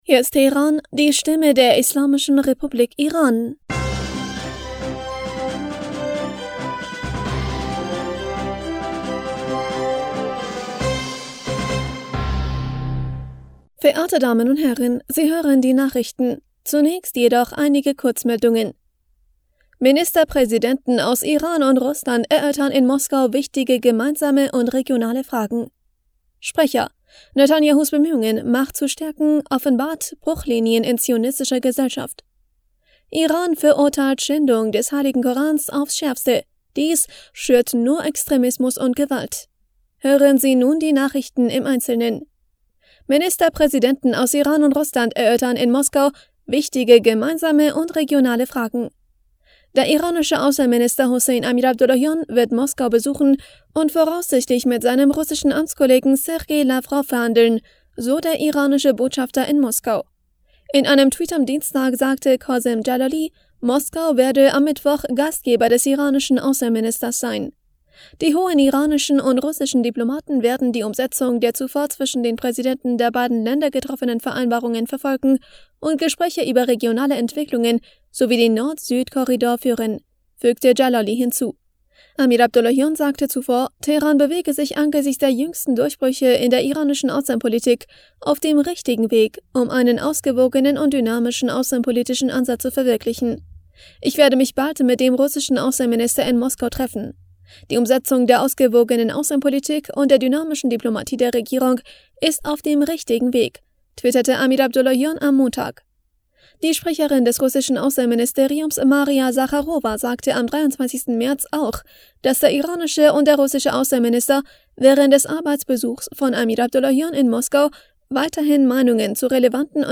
Nachrichten vom 28. März 2023